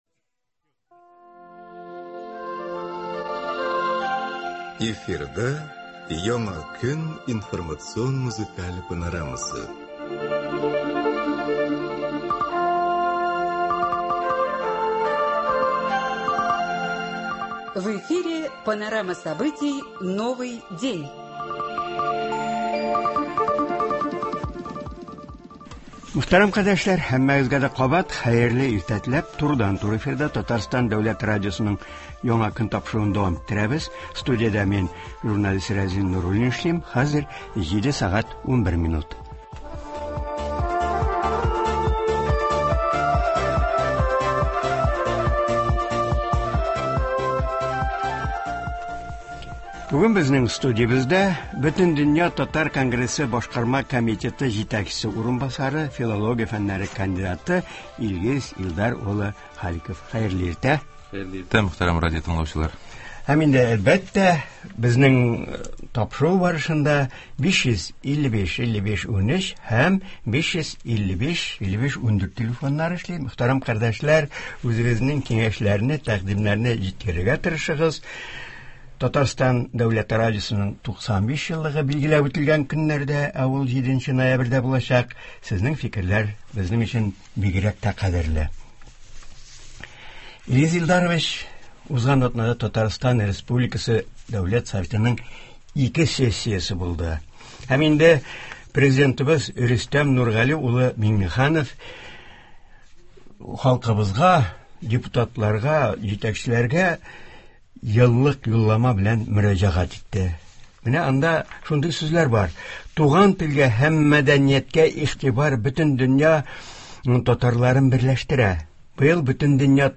Туры эфир (24.10.22)